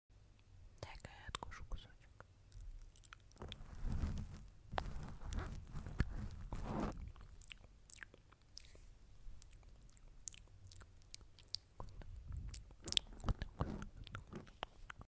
|ASMR| let me take a sound effects free download
|ASMR| let me take a bite